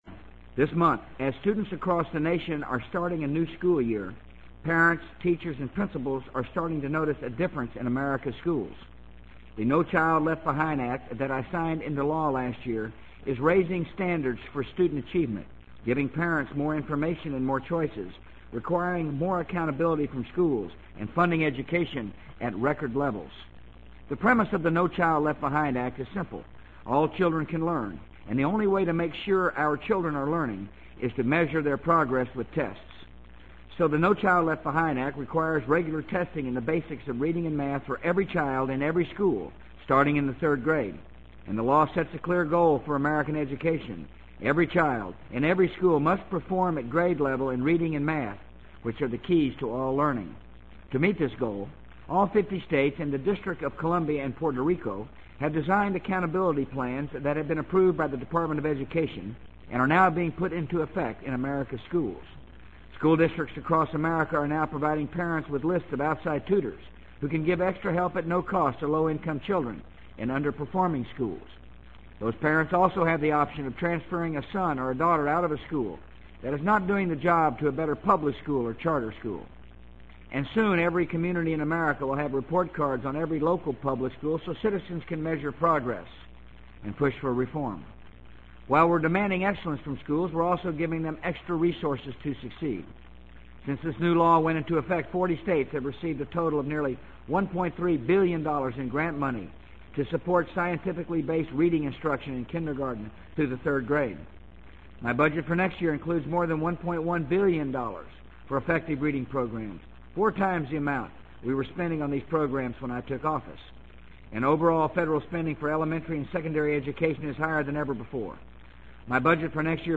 【美国总统George W. Bush电台演讲】2003-09-06 听力文件下载—在线英语听力室